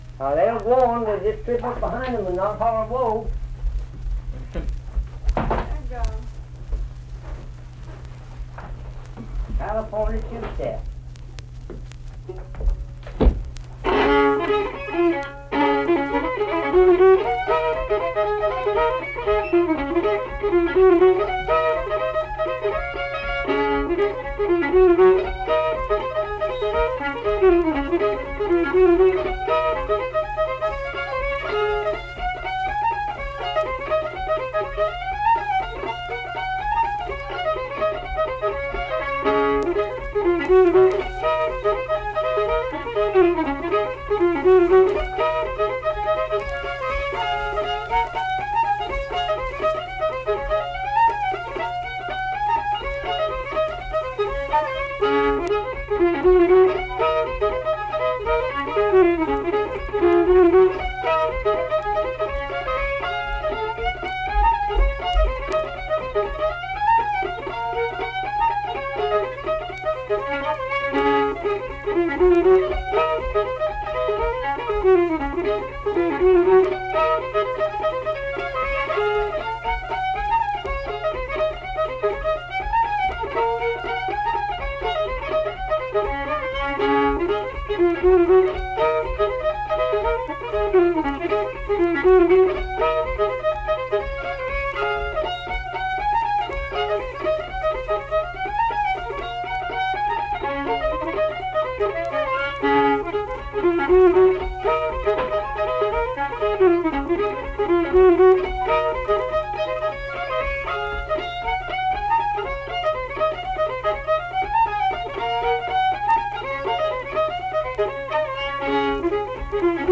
Unaccompanied fiddle music
Instrumental Music
Fiddle
Flatwoods (Braxton County, W. Va.), Braxton County (W. Va.)